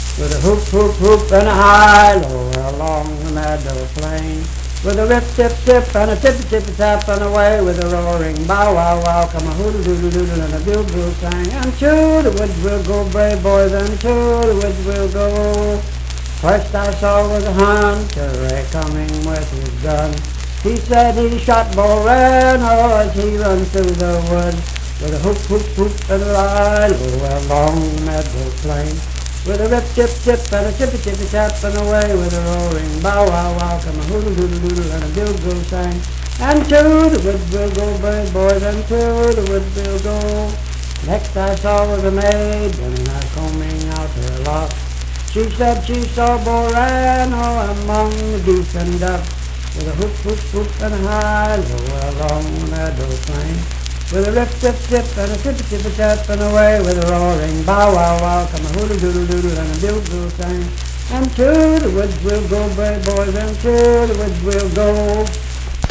Unaccompanied vocal performance
Verse-refrain 2 (4) & R (7).
Voice (sung)
Harrison County (W. Va.)